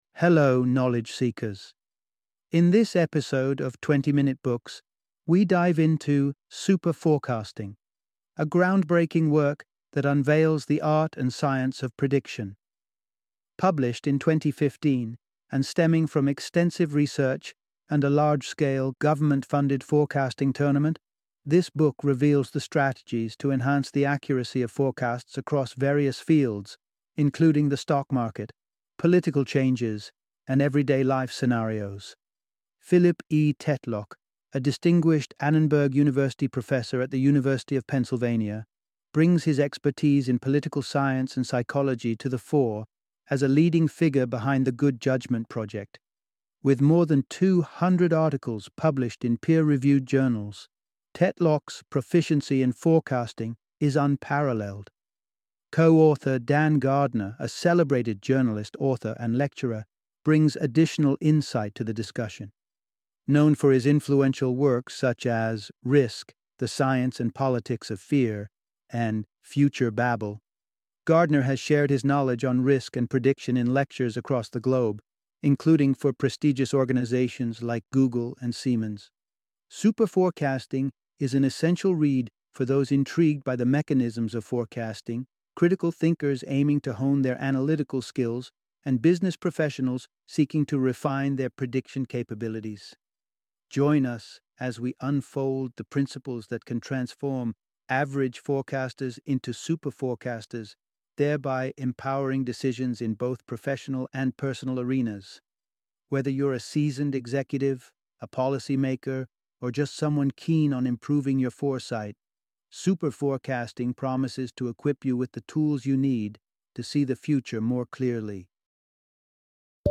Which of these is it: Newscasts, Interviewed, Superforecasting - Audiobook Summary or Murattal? Superforecasting - Audiobook Summary